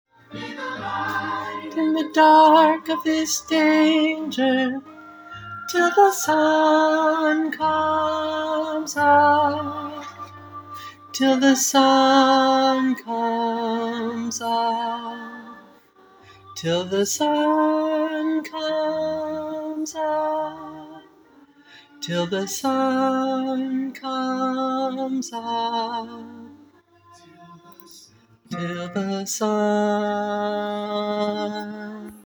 [Choir] Practice recordings - be the hand